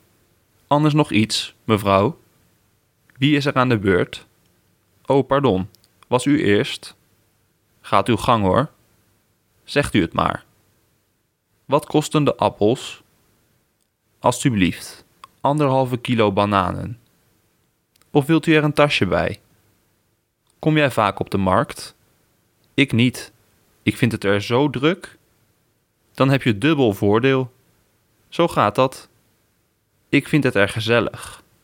Przesłuchaj zdania wypowiedziane przez  holenderskiego native speakera i przekonaj się sam!